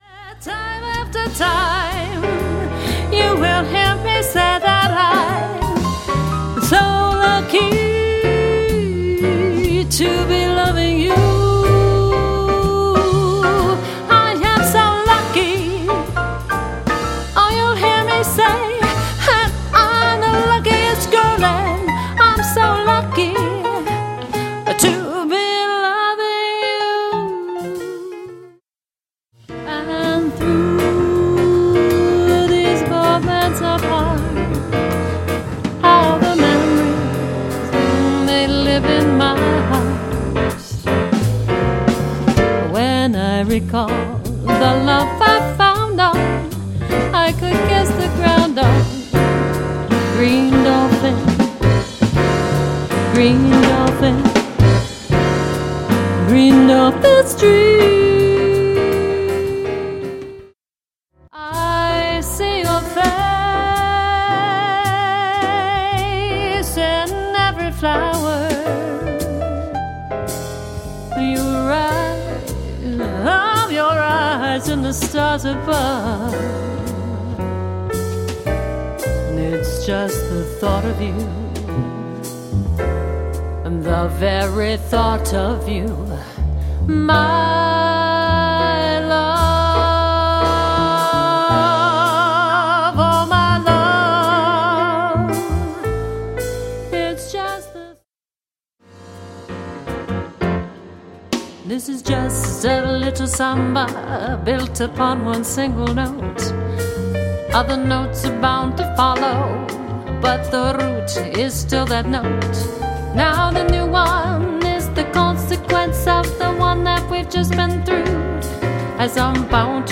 Singing Demo
Jazz Quartet Demo Sample.mp3